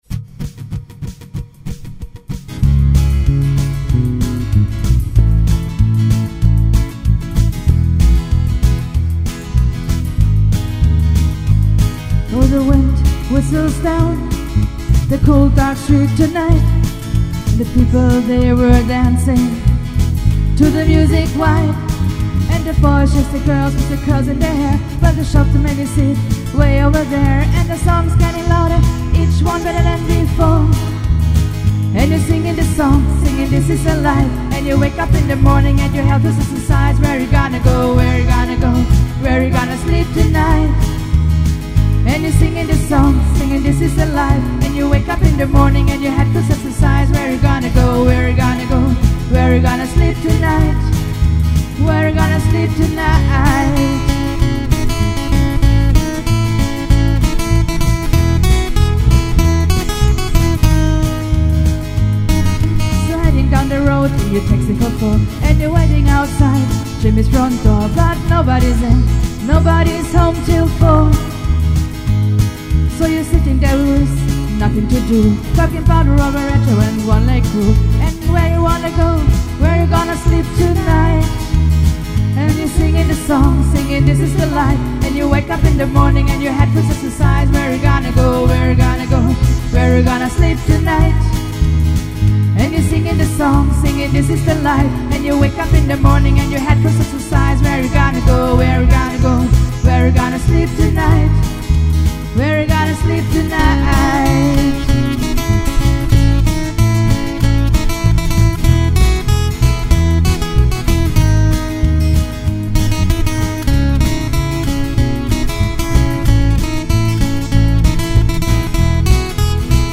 • Coverband
• Sänger/in